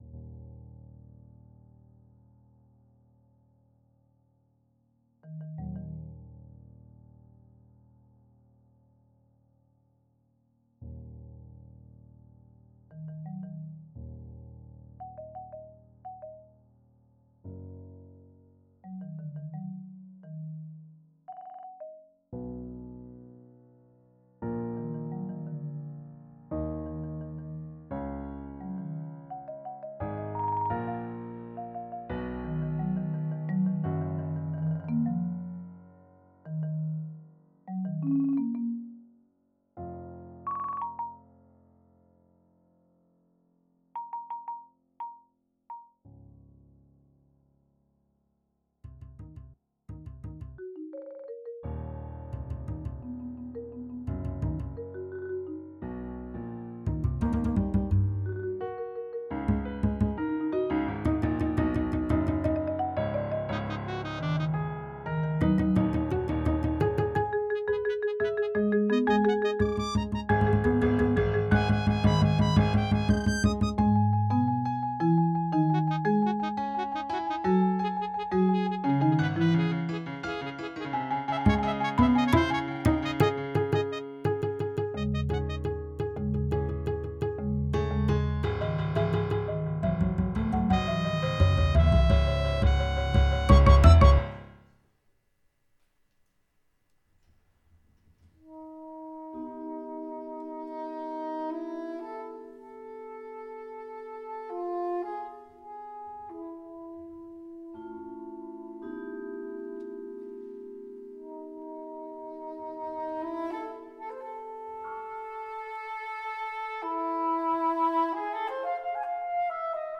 Midi and performance audio demo